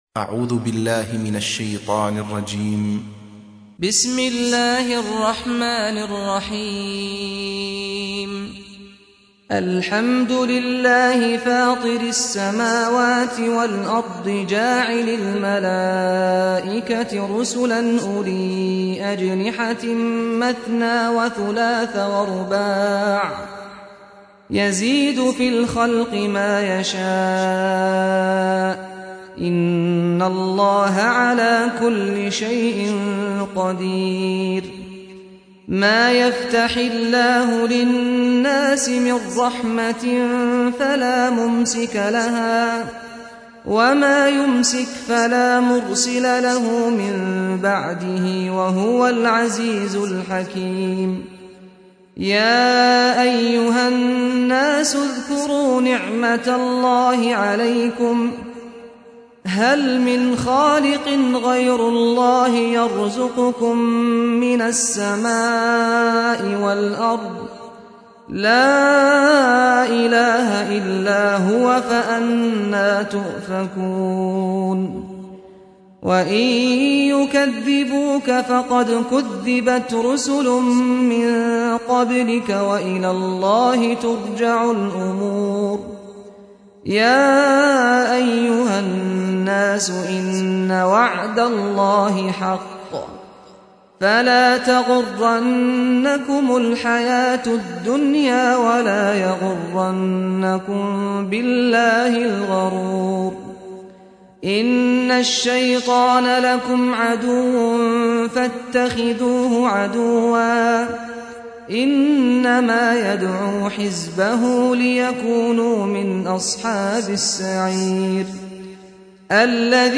سُورَةُ فَاطِرٍ بصوت الشيخ سعد الغامدي